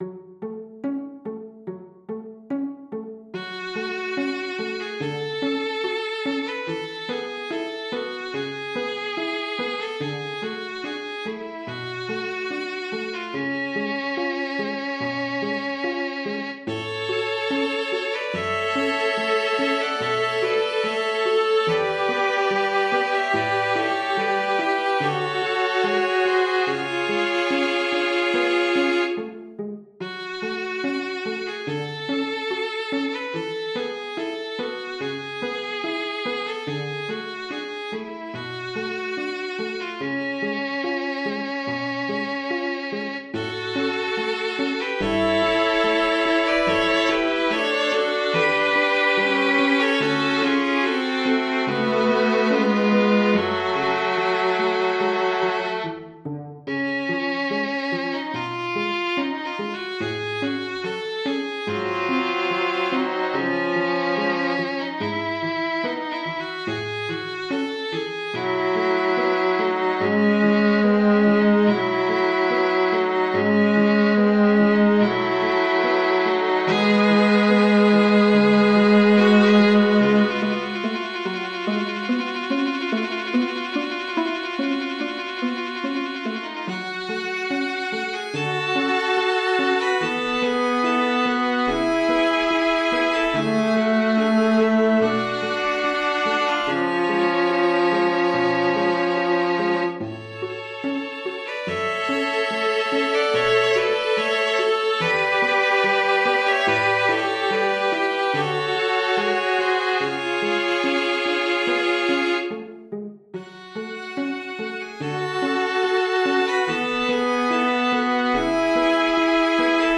for String Quartet
Voicing: String Quartet